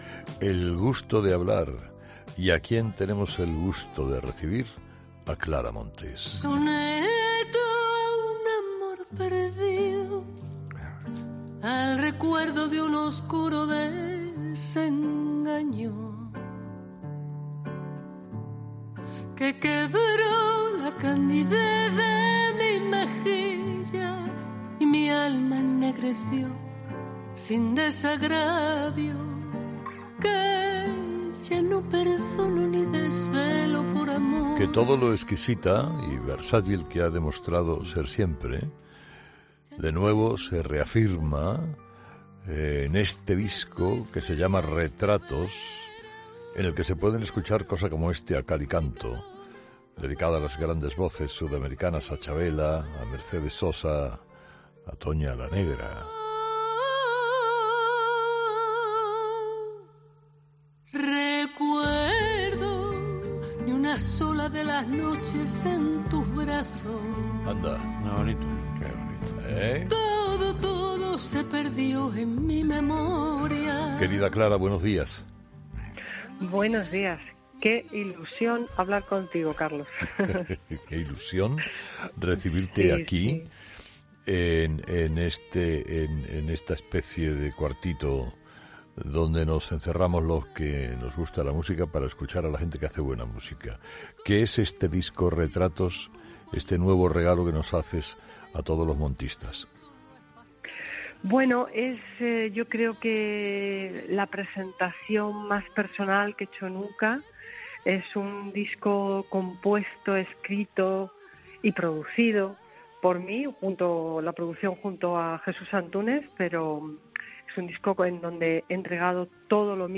La cantante madrileña ha presentado en "Herrera en COPE" su disco más personal que ha escrito, compuesto, arreglado e incluso producido